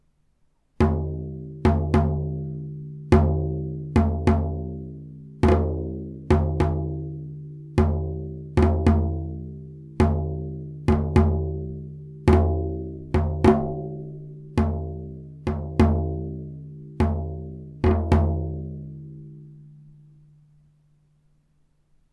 march_drum.mp3